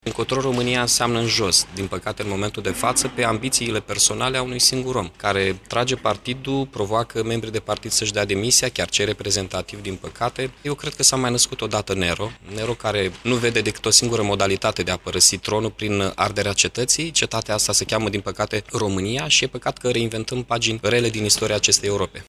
Primarul Iașului, Mihai Chirica: